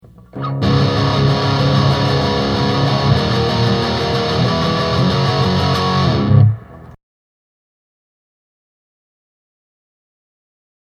Whenever I record guitar and play it back, it sounds like it was recorded in a cave or something. It sounds distant and just isn't full sounding.
Electric, and when I say basic mic, I just mean a cheap $20 mic.